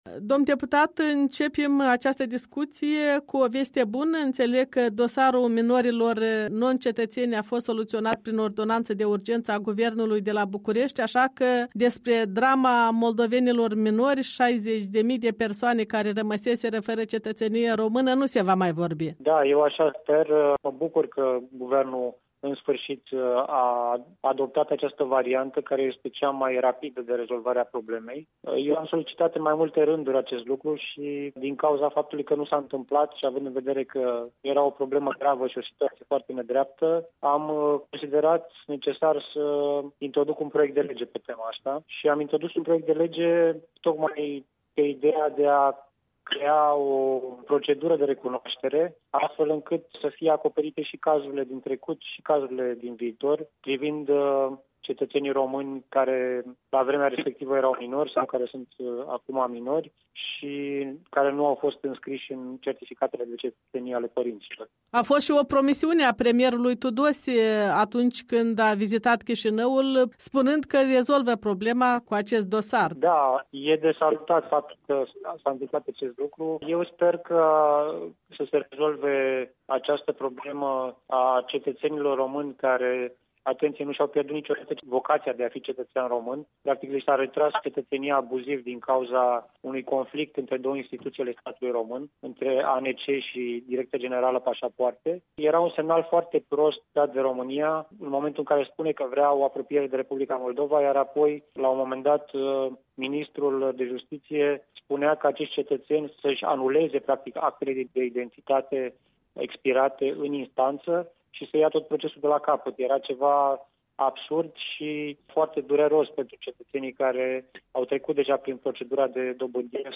Interviu cu Matei Adrian Dobrovie